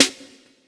Snares
pcp_snare15.wav